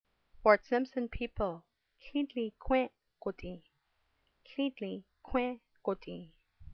pronounce Łı́ı́dlı̨ı̨ Kų́ę́ People), elder and youth voices, and their self-determination as land stewards.